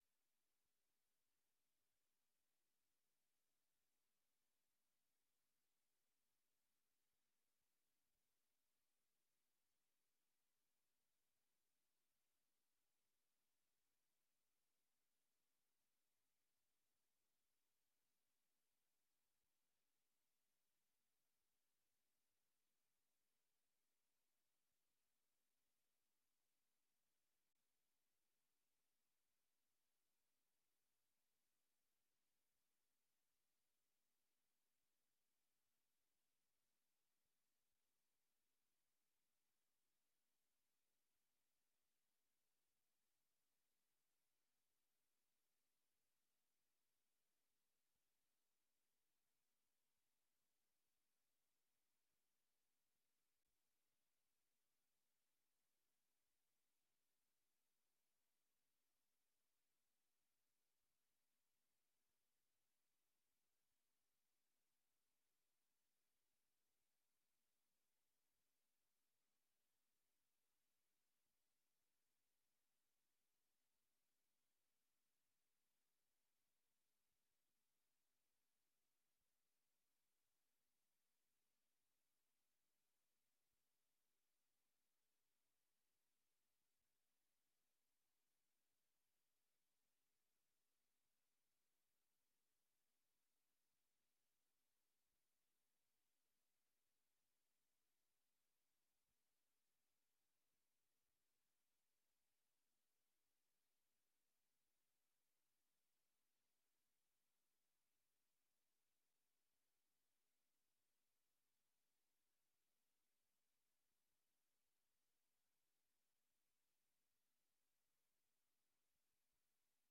Beeldvormende vergadering 01 september 2022 19:30:00, Gemeente Dronten
Download de volledige audio van deze vergadering